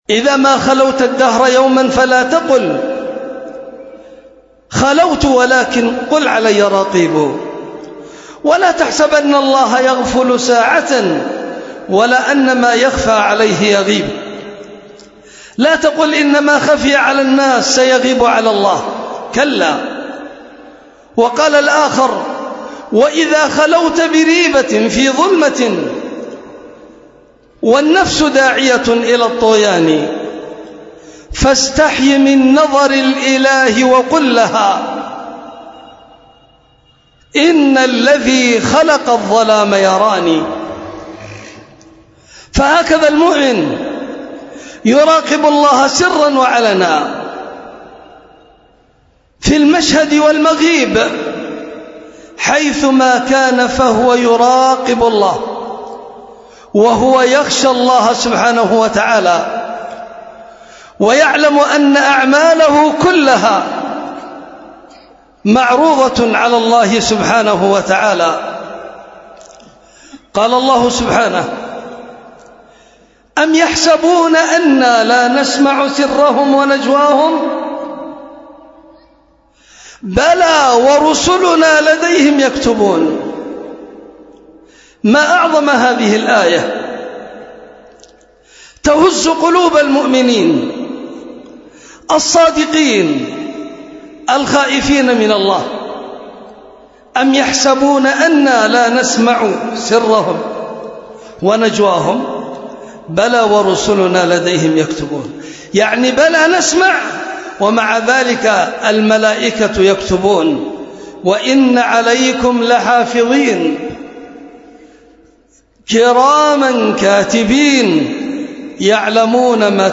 خطبة